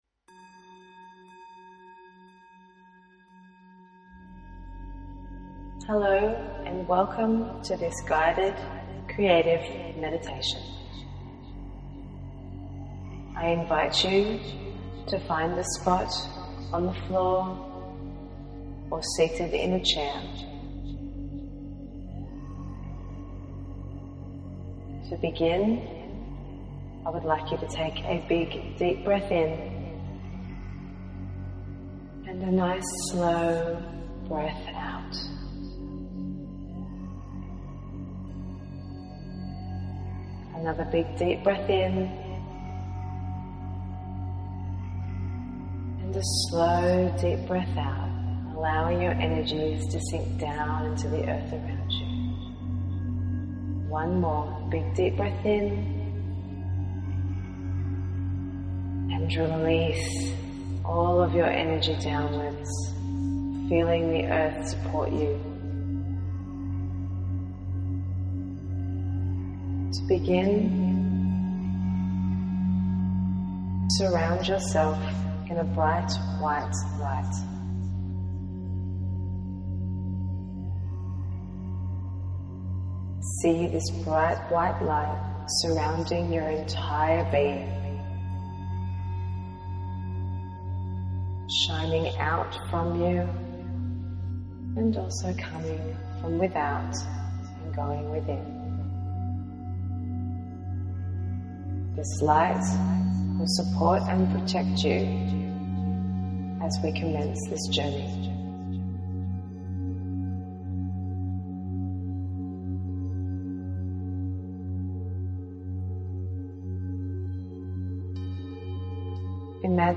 Creative Guided Meditation